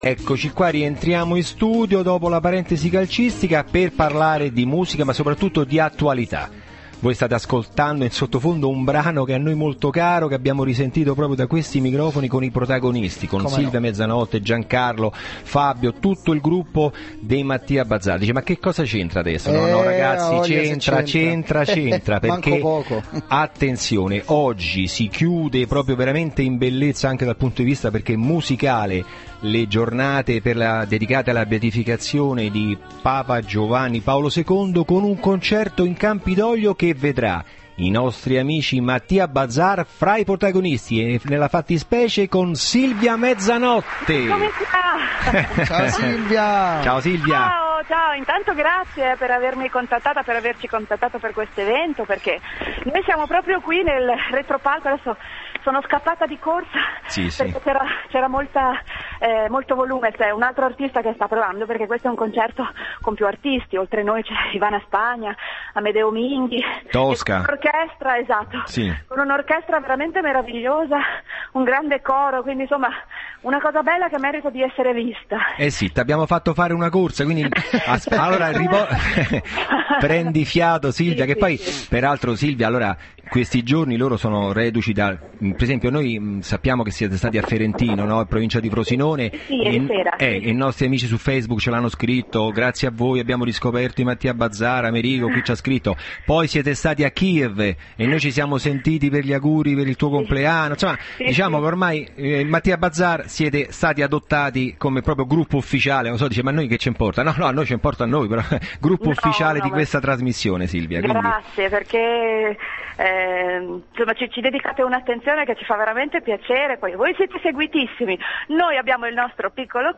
Intervento telefonico Silvia Mezzanotte (Matia Bazar)
ascolta_intervento_silvia_mezzanotte_matia_bazar.mp3